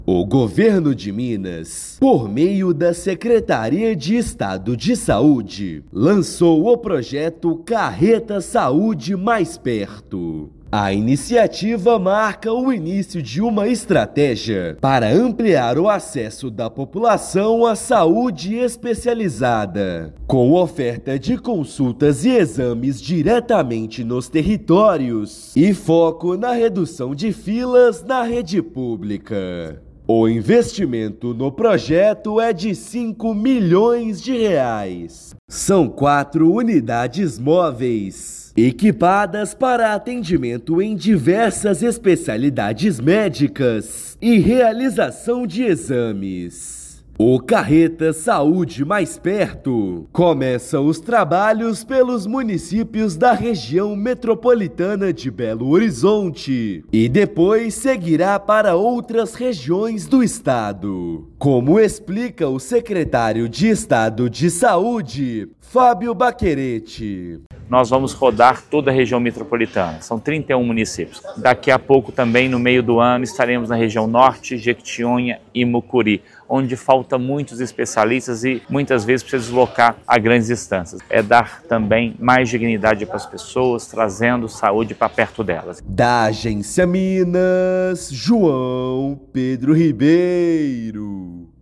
Unidades móveis aumentam acesso à saúde especializada, com consultas e exames em regiões com maior vulnerabilidade. Ouça matéria de rádio.